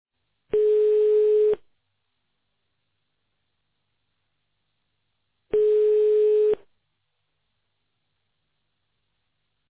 outbound_ring.ogg